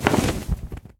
dragon_wings5.ogg